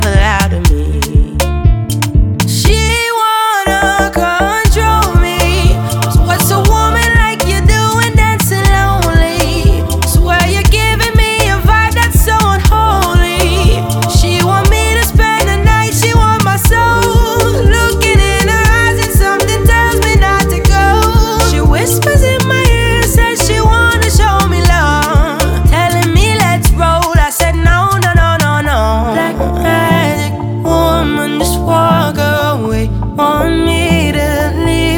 Afro Soul African Afro-Pop
Жанр: Поп музыка